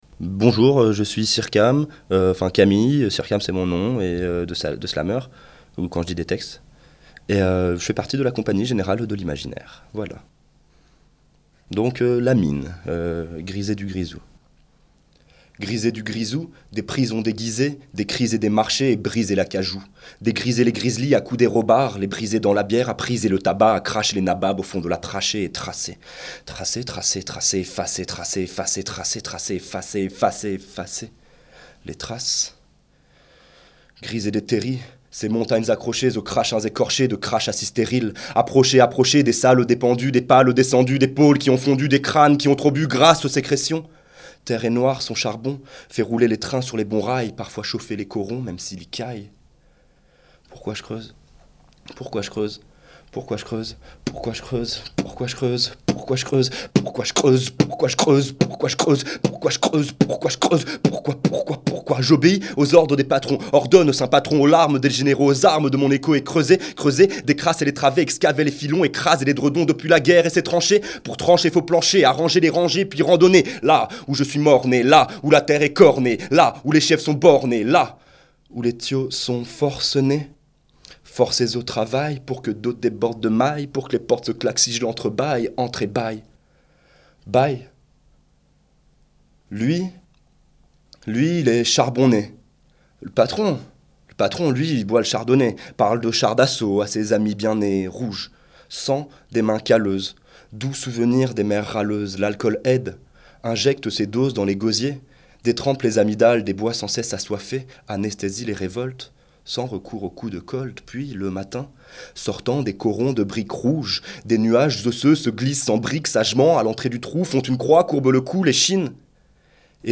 ateliers slam , écriture et enregistrement de séquences
séquence slam 2